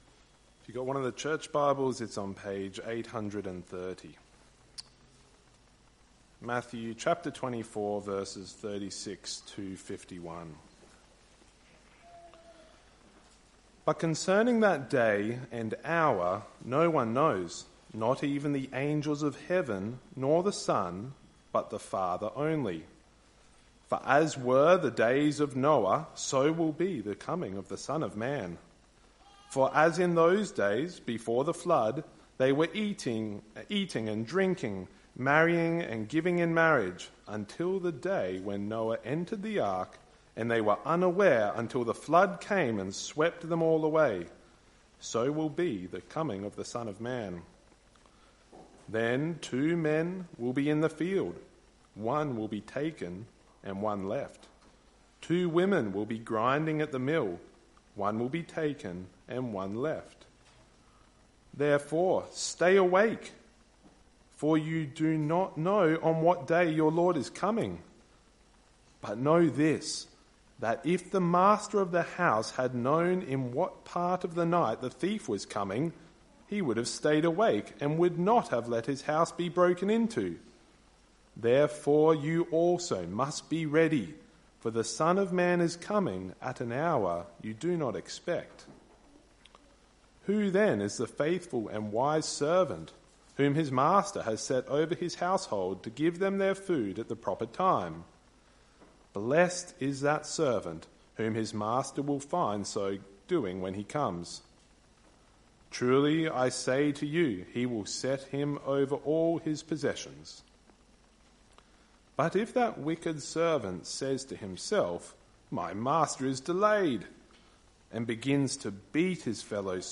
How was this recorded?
Sunday Evening - 19th October 2025